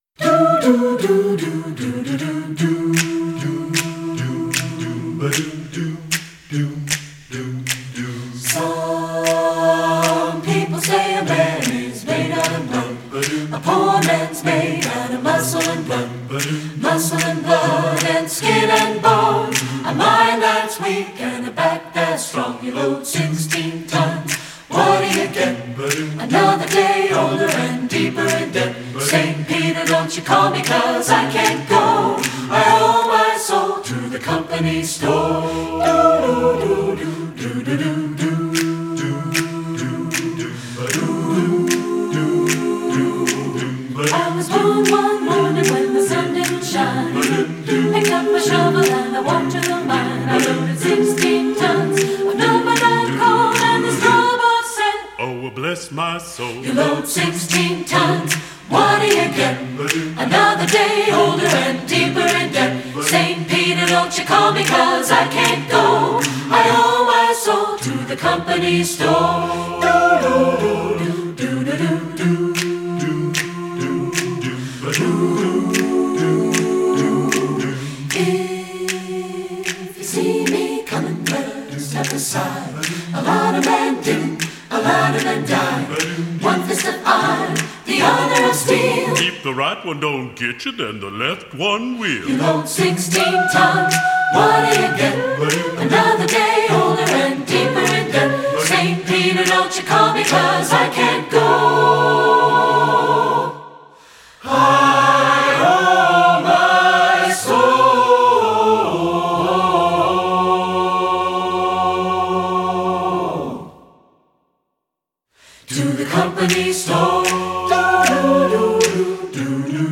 Voicing: TBB